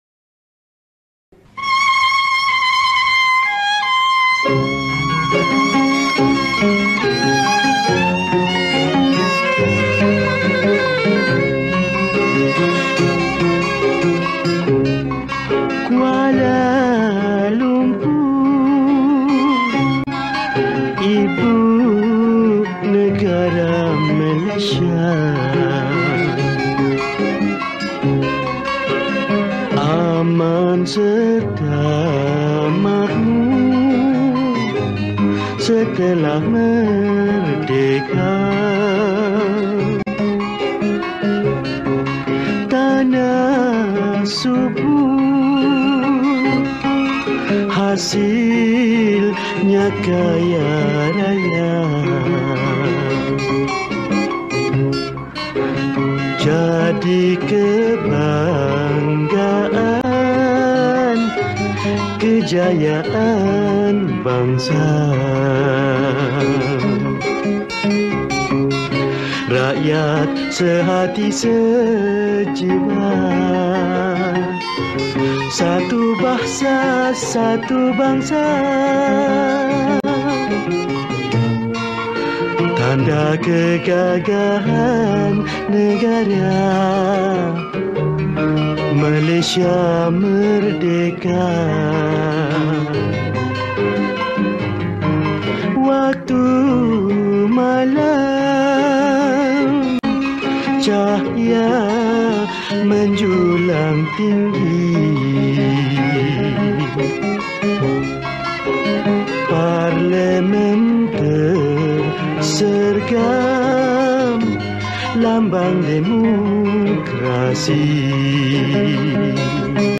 Patriotic Songs